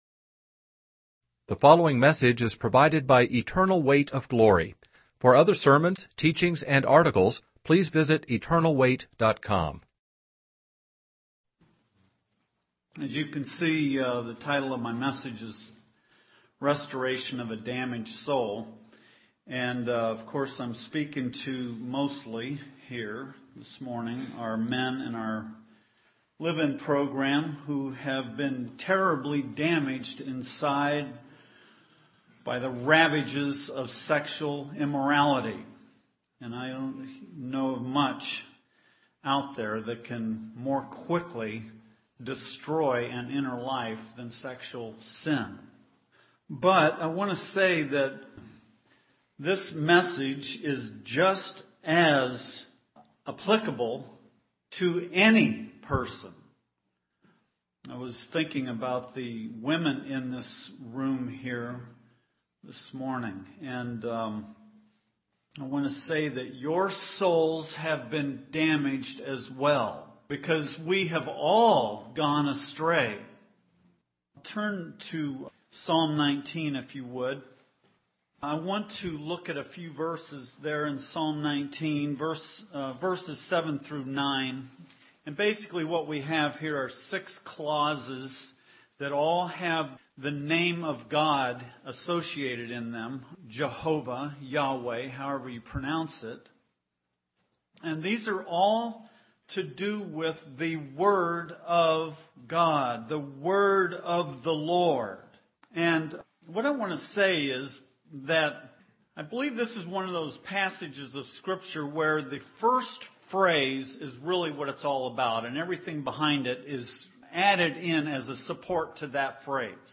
In this sermon, the speaker emphasizes the importance of choosing the Word of God over the empty cisterns of the world. He warns that after graduating from a program, there will be a temptation to turn back to worldly distractions like television and the internet.